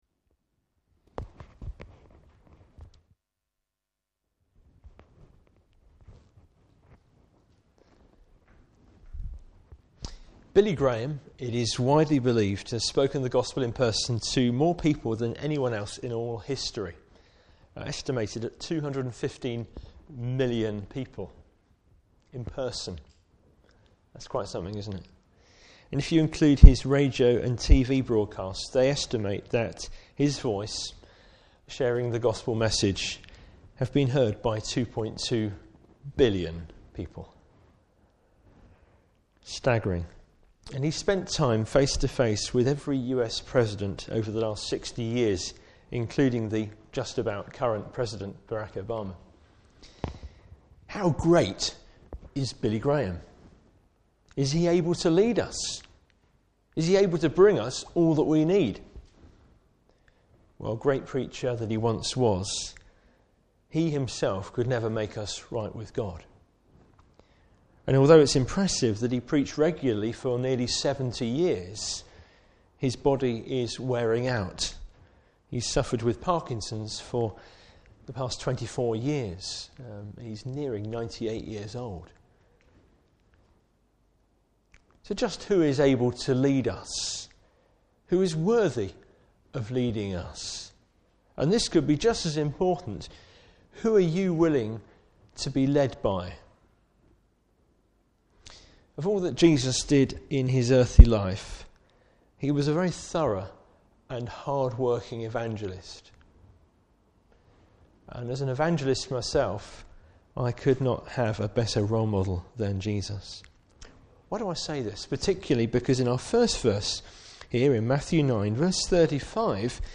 Service Type: Morning Service Bible Text: Matthew 9:35-38.